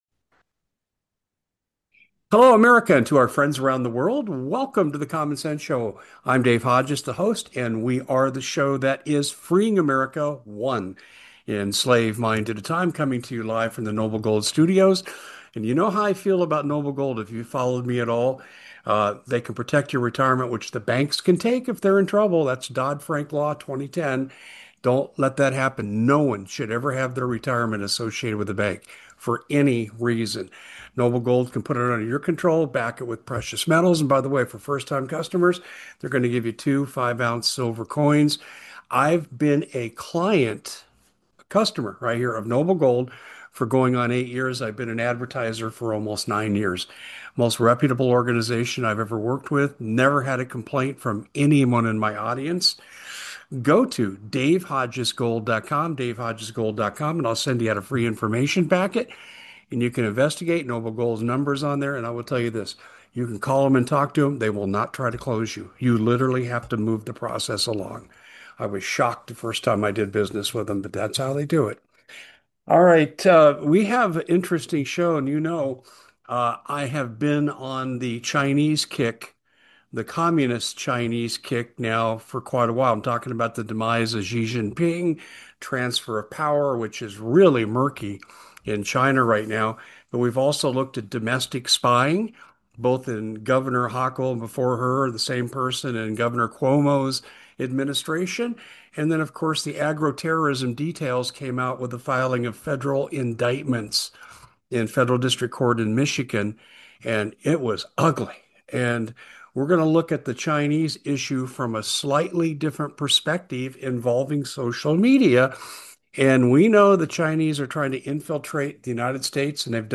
Through interviews with experts, he uncovers concerns about apps like TikTok and the presence of Chinese students in U.S. universities. The discussion highlights the CCP's tactics, including spreading propaganda and controlling narratives, which could impact American society and global perspectives.